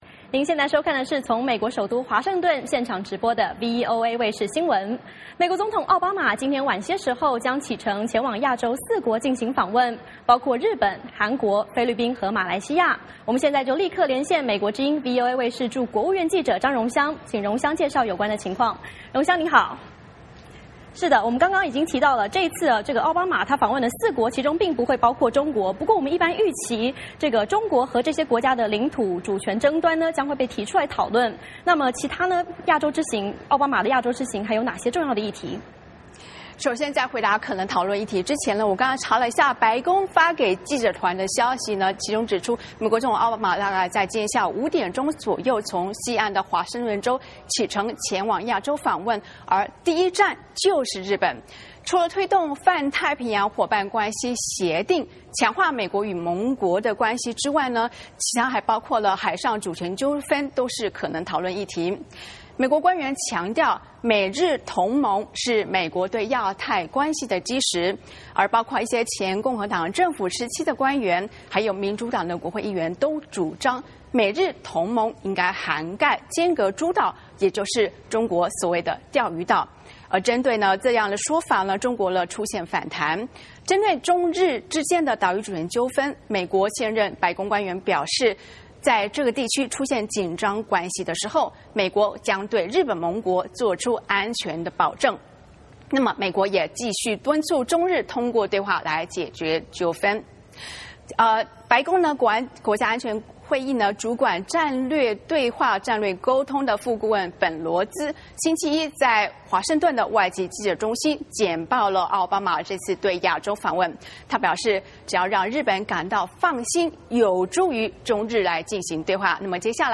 VOA连线：美：重申美日同盟有助中日对话